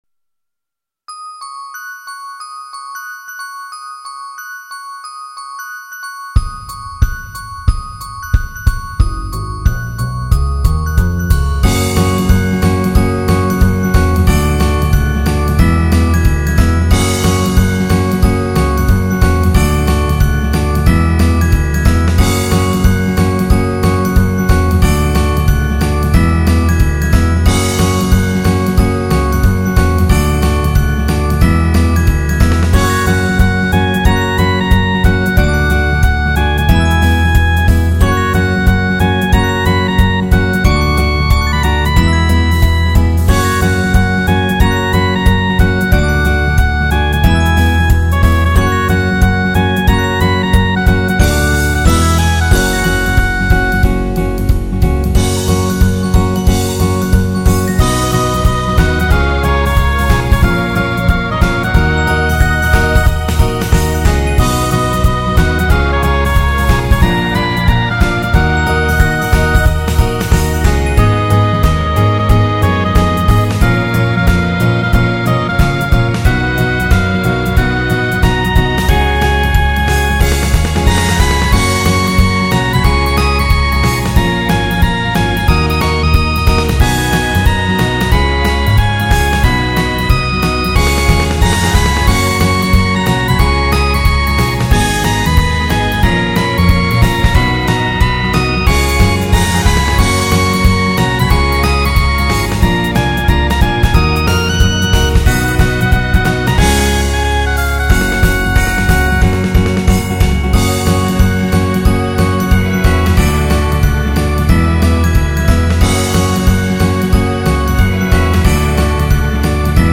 歌詞   ラブジャズポップ
Music Box、ギター、ベース